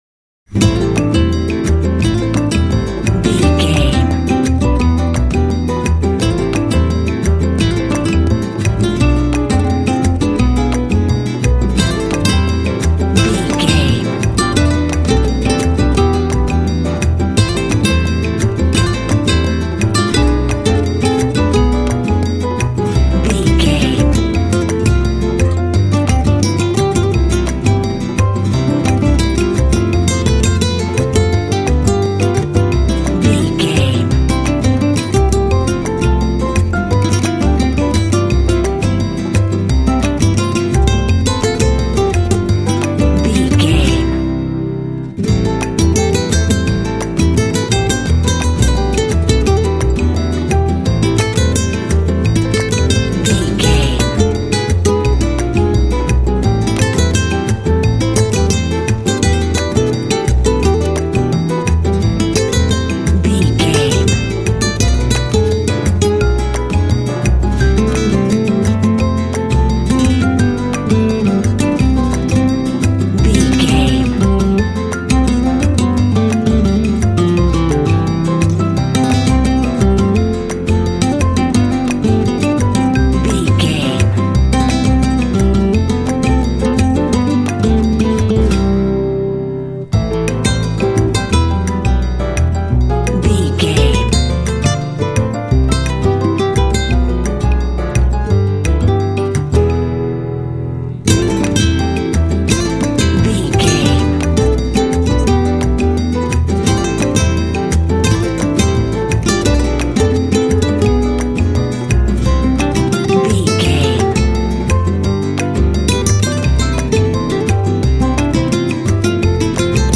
Aeolian/Minor
sexy
smooth
sensual
acoustic guitar
piano
percussion
double bass
latin
flamenco
mambo
rhumba